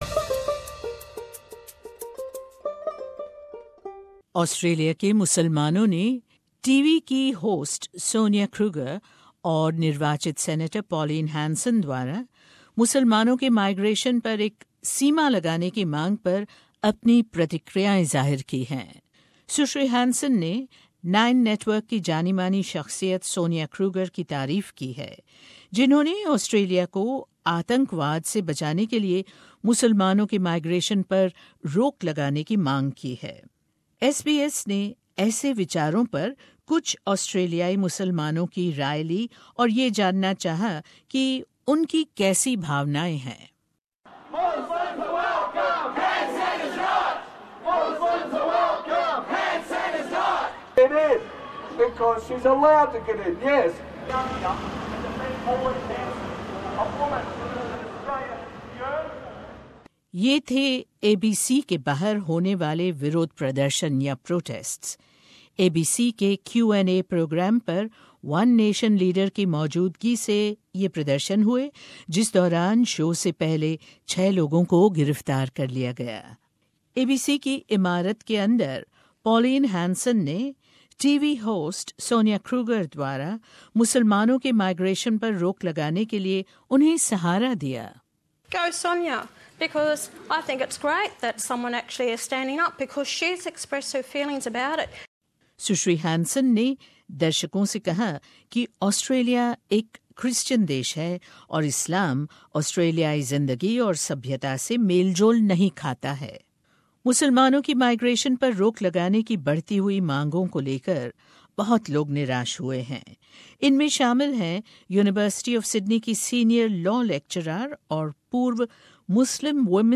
ऑस्ट्रेलिया के मुसलामानों ने TV की होस्ट सोनिआ क्रूगर और निर्वाचित सीनेटर पॉलिन हेंसन द्वारा मुसलामानों की माइग्रेशन पर सीमा लगाने की मांग पर अपनी प्रतिक्रियाएं ज़ाहिर की हैं। सुनिए ये रिपोर्ट।